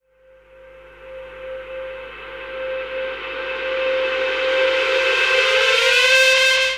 VEC3 Reverse FX
VEC3 FX Reverse 34.wav